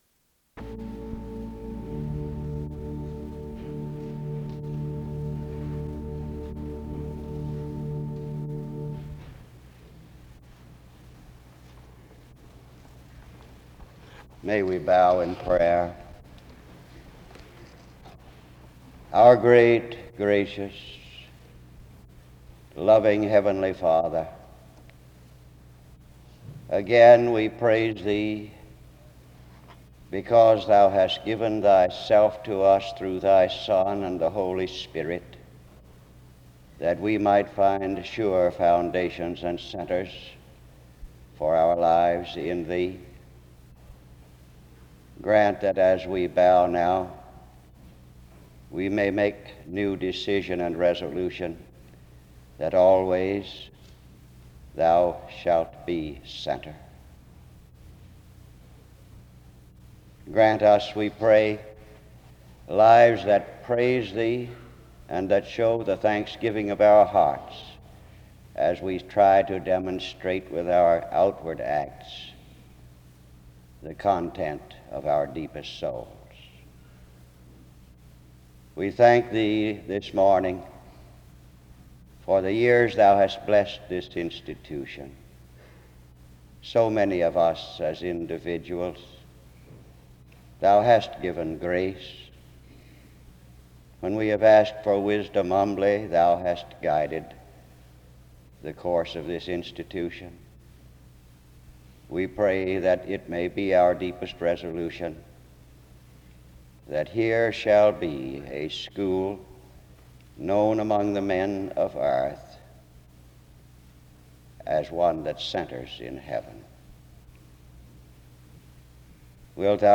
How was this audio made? The service begins with prayer (00:00-03:01), a recognition of the Board of Trustees for this Founder’s Day Address (03:02-07:24), and a word of resignation from the chairman of the Board of Truste...